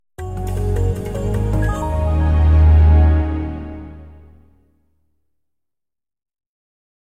Sonido Original